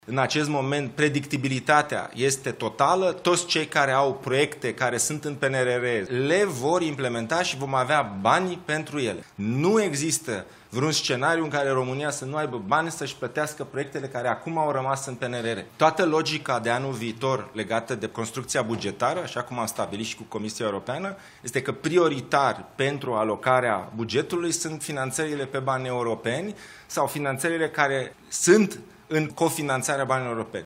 Ministrul Investițiilor și Proiectelor Europene, Dragoș Pîslaru: „Nu există vreun scenariu în care România să nu aibă bani să își plătească proiectele care acum au rămas în PNRR”